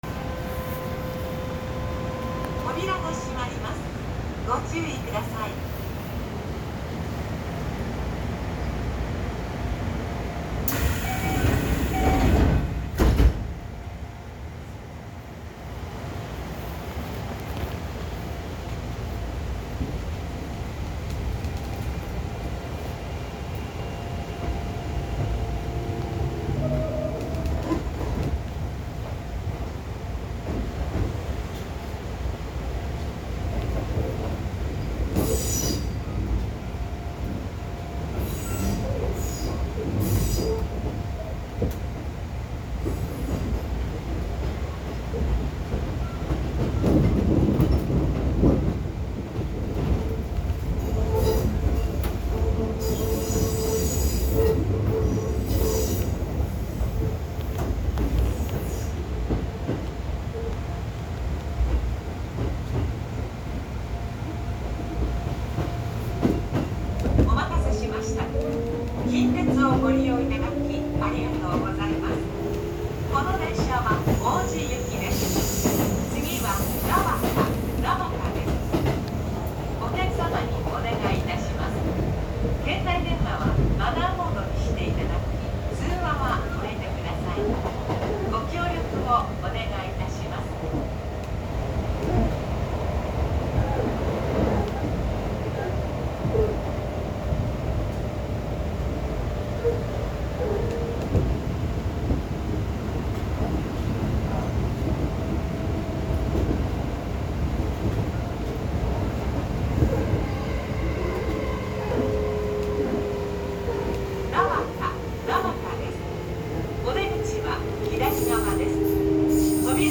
・1220系統(日立GTO)走行音
【生駒線】生駒→菜畑→一分
VVVFは日立製で、西武6000系や京王8000系等と同系統の音のはずなのですが、一度転調してからは全く異なる音になってしまいます。こんな風に、近鉄は独自の音を出すGTO車が多い印象です。